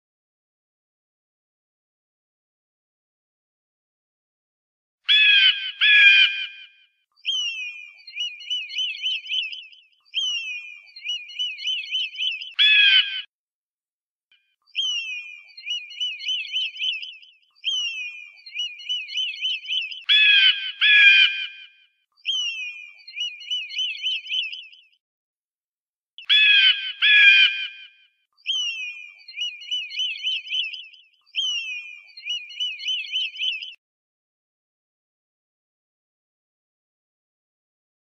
Aguila Real
Sonido del Ã-guila.sonidos cortos de animales.mp3